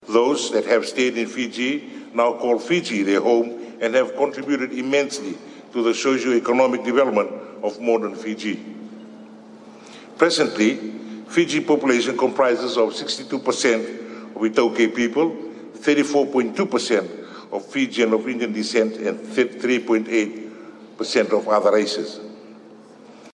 While opening the 12th World Hindi Conference in Nadi, President of Fiji Ratu Wiliame Katonivere says the relationship between the two nations can be traced back to colonel heritage.